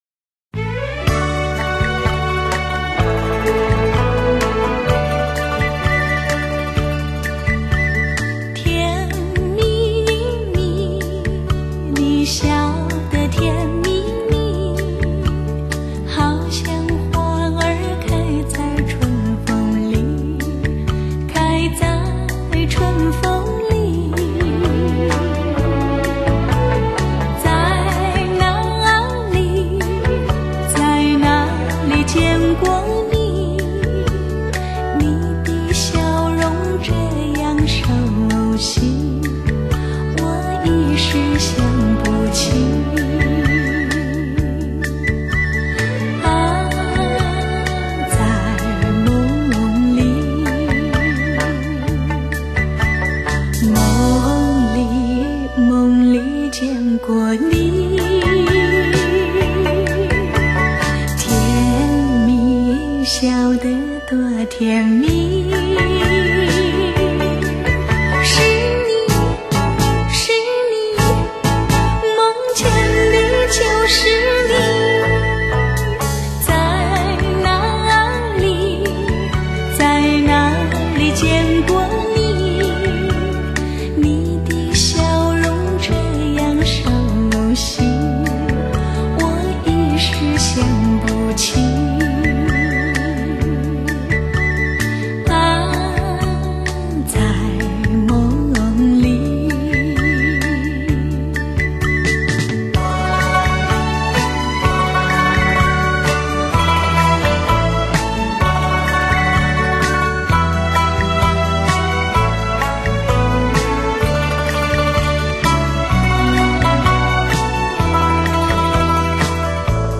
全碟音色传真无比  层次鲜明  人声通透圆润
器乐真实  原汁原味  实为不可多得的原音极品[center]
技发烧片音质处理，动态强、音场准确精彩、低频雄浑、音色更具HI-FI。